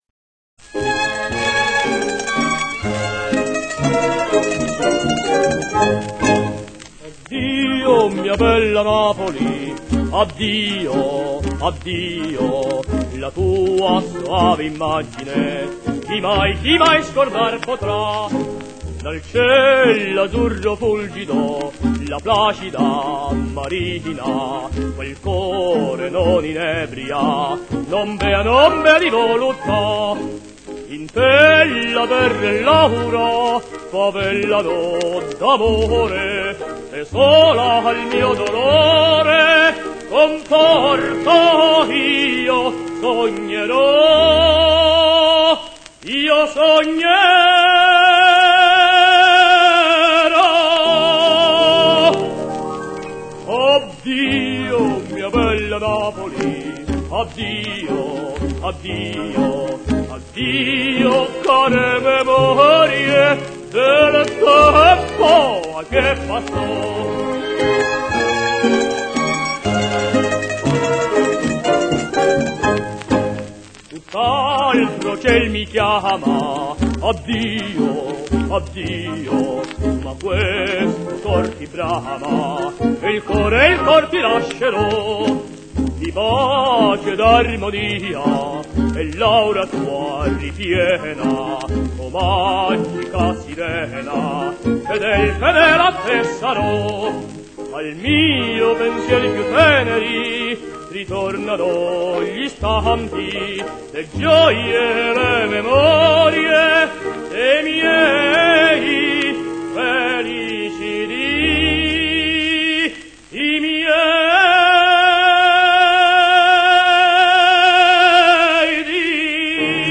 tenor